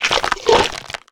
Minecraft Version Minecraft Version snapshot Latest Release | Latest Snapshot snapshot / assets / minecraft / sounds / mob / camel / eat2.ogg Compare With Compare With Latest Release | Latest Snapshot
eat2.ogg